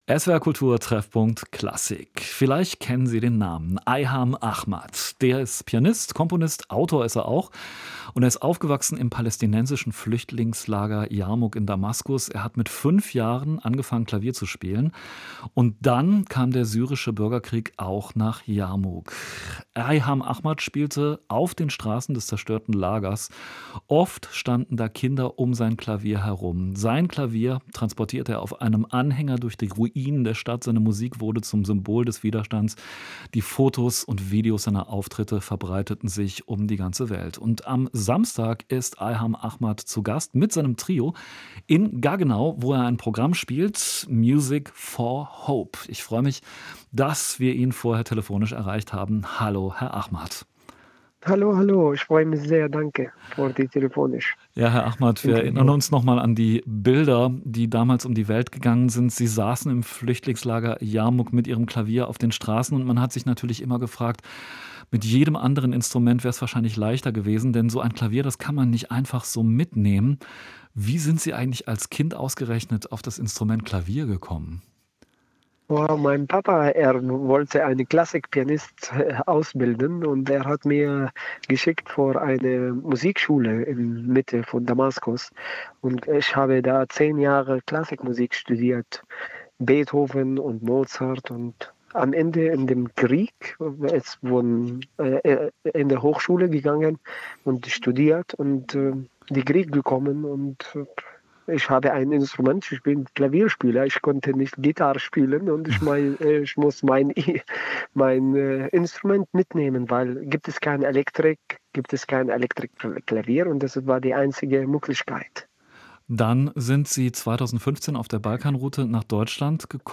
Bei SWR Kultur spricht er über seine musikalischen Anfänge und sein aktuelles Programm „Music for Hope“.
Interview mit